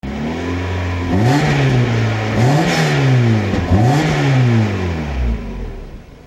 dooropen.mp3